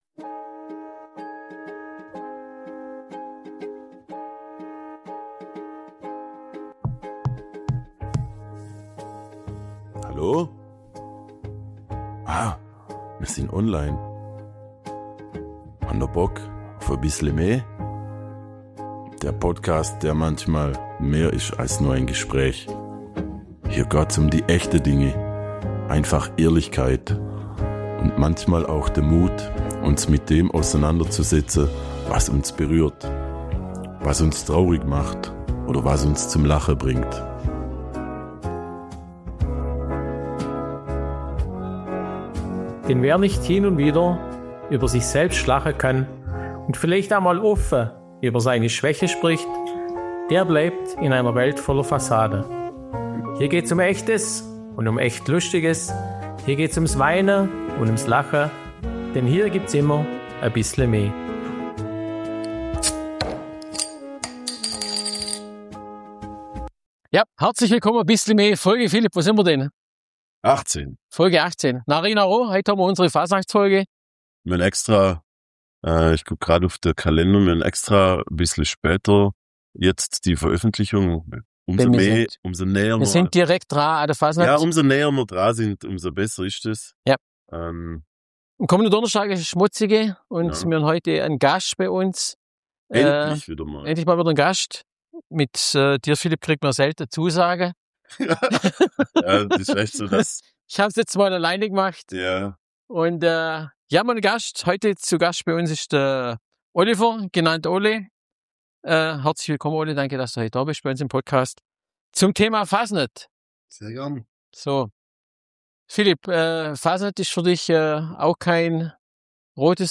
#18 Narri Narro, Hotzenwalder Hennaverwürger und ganz viel Tradition ~ Bissle me – Schwoba-Podcast aus´m Schlofsack Podcast